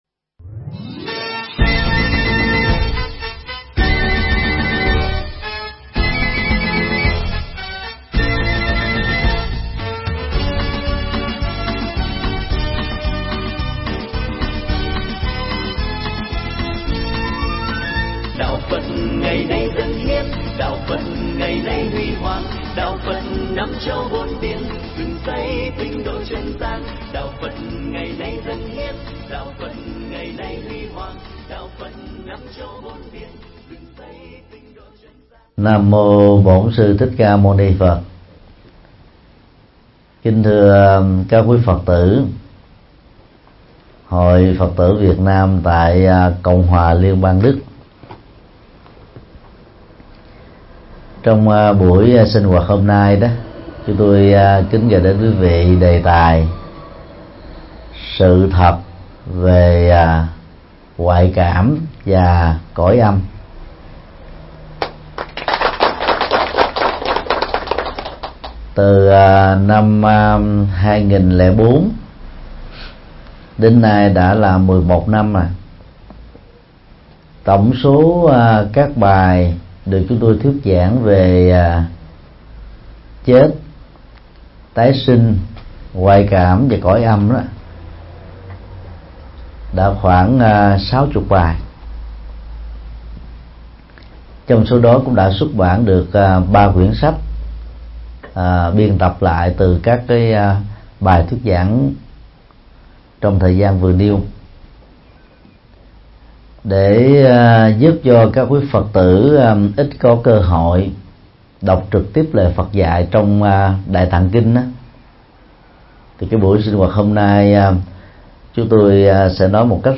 Mp3 Pháp Thoại Sự thật về ngoại cảm và cõi âm - Thầy Thích Nhật Từ giảng tại Chợ Đồng Xuân Berlin, Đức Quốc, ngày 26 tháng 7 năm 2015